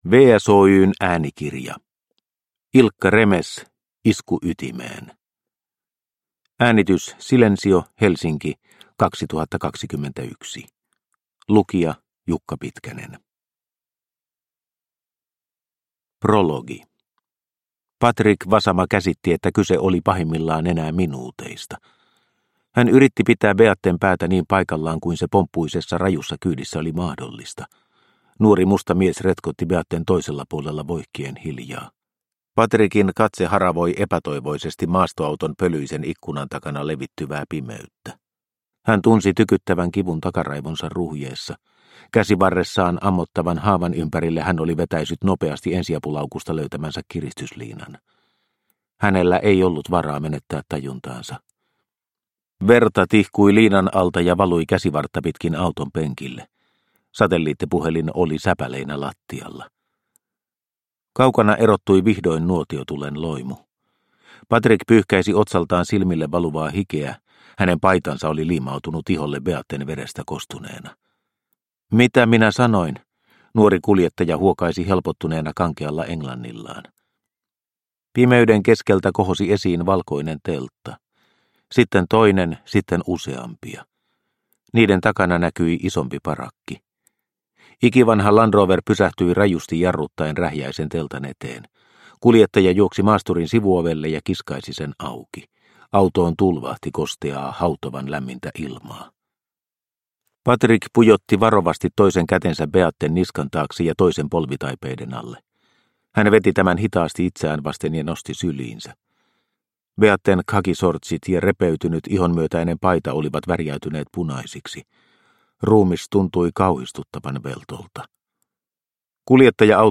Isku ytimeen – Ljudbok – Laddas ner